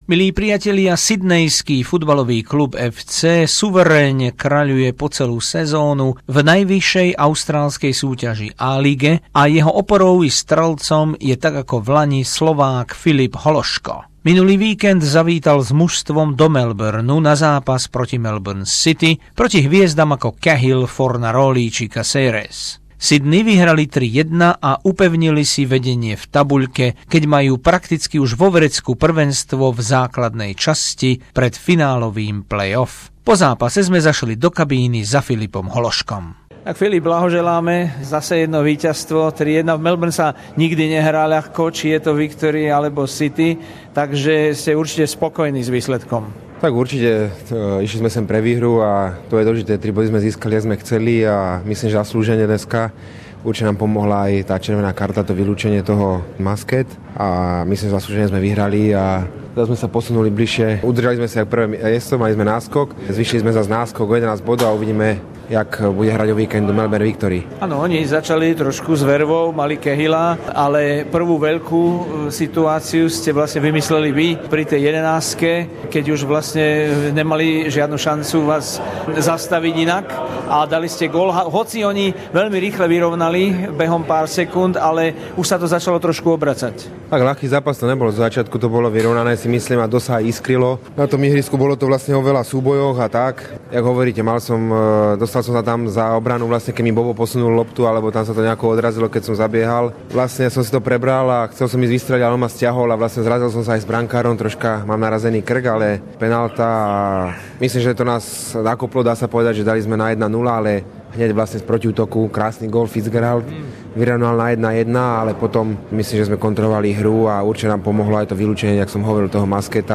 Rozhovor s futbalistom Sydney FC Filipom Hološkom
Slovák Filip Hološko po zápase v Melbourne proti City